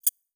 Holographic UI Sounds 97.wav